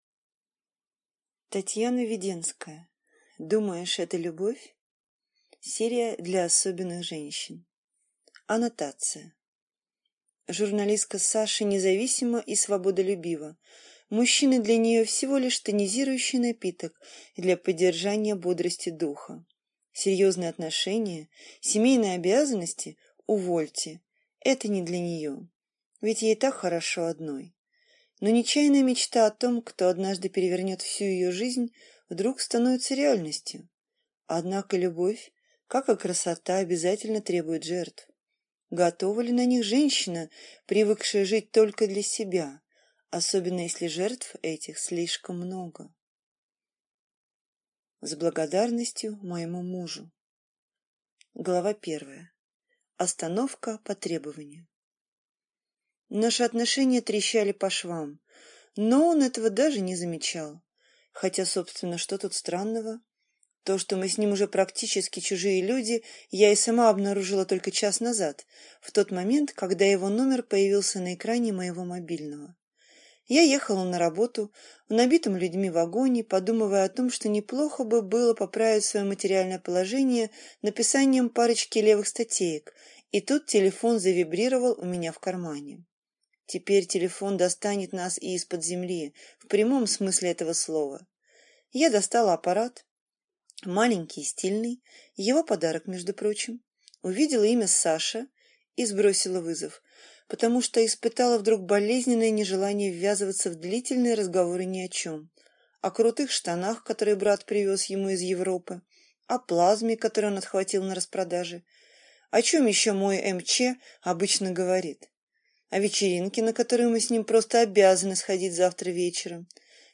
Аудиокнига Думаешь, это любовь?